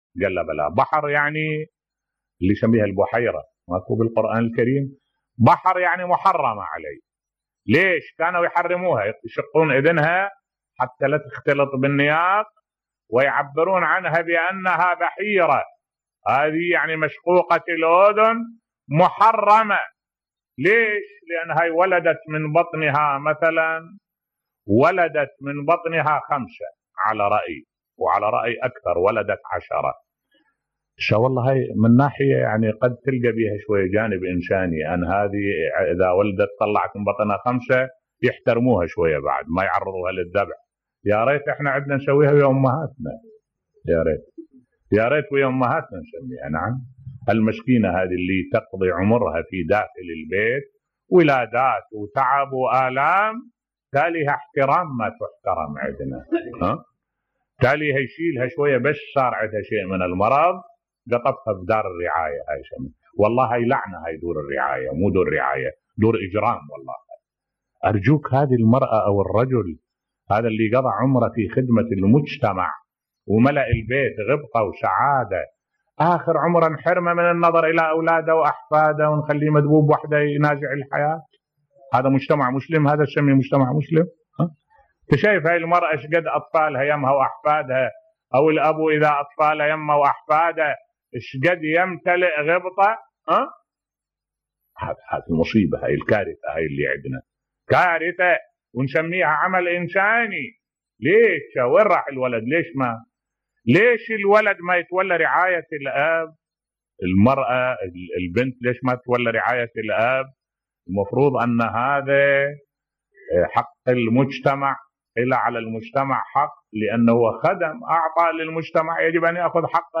ملف صوتی دور رعاية المسنين بصوت الشيخ الدكتور أحمد الوائلي